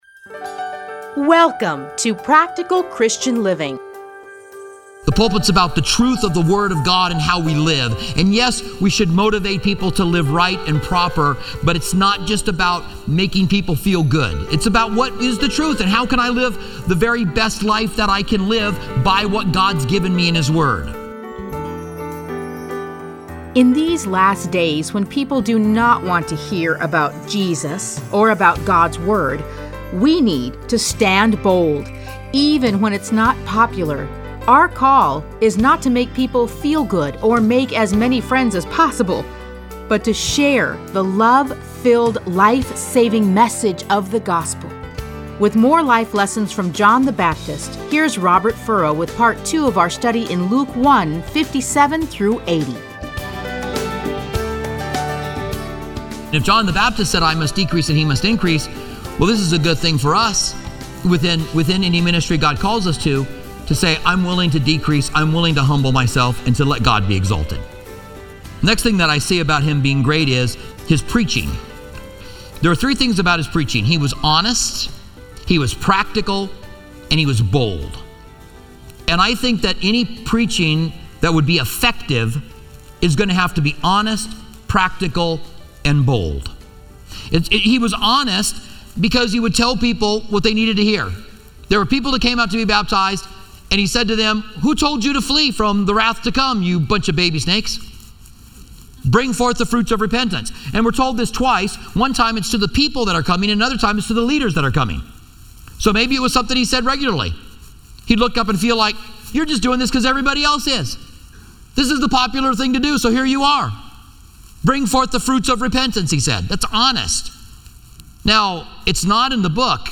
Listen to a teaching from Luke 1:57-80.